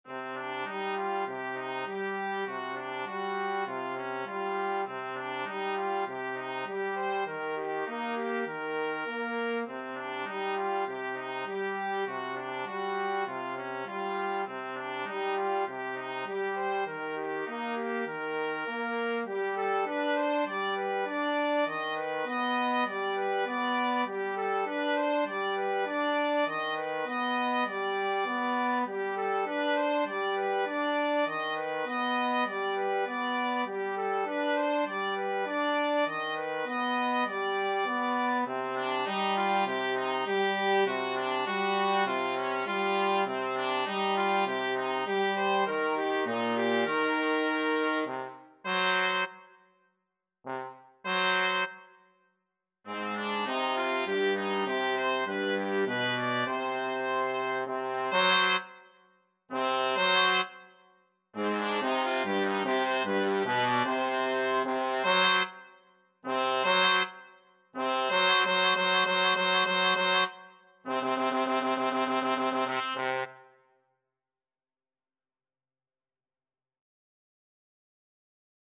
4/4 (View more 4/4 Music)
C minor (Sounding Pitch) D minor (Trumpet in Bb) (View more C minor Music for Trumpet-Trombone Duet )
Trumpet-Trombone Duet  (View more Intermediate Trumpet-Trombone Duet Music)
Classical (View more Classical Trumpet-Trombone Duet Music)